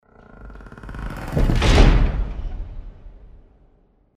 Звуки ворот
Ворота захлопнулись